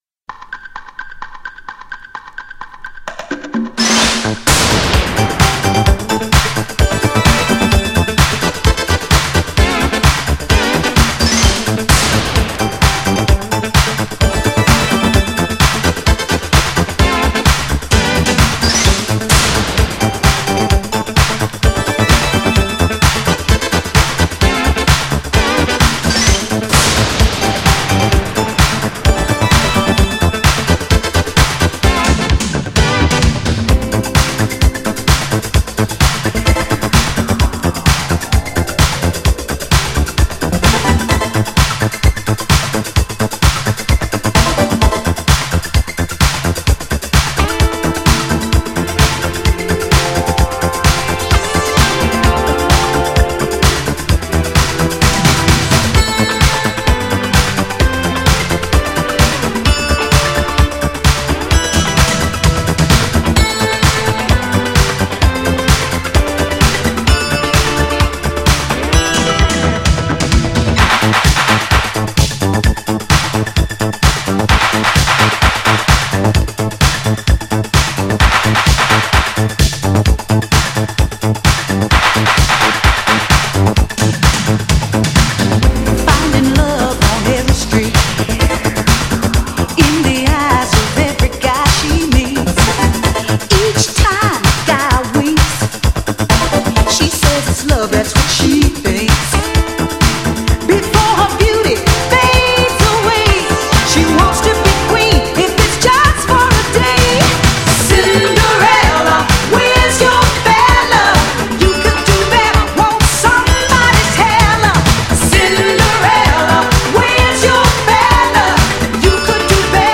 GENRE Dance Classic
BPM 131〜135BPM
# EURO_DISCO
# エレクトロ # ハイエナジー